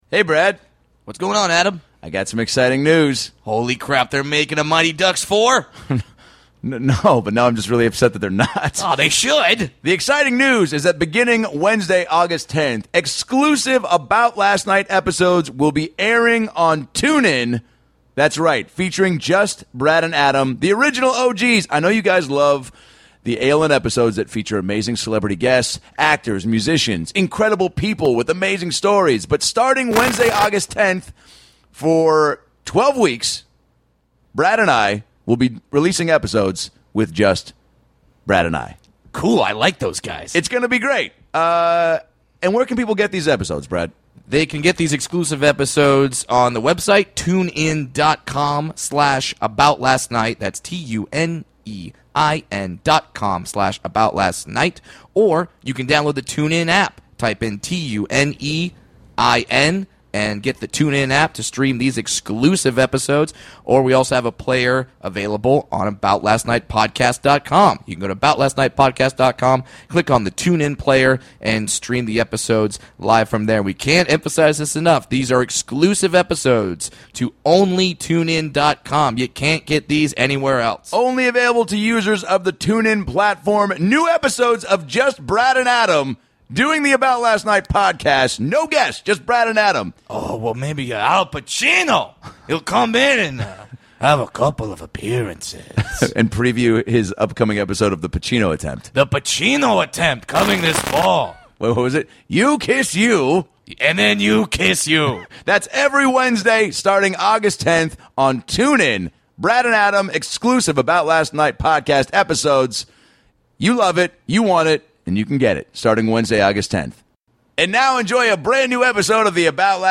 Lotta laughs in this one, but a lot of conversation you need to hear to believe.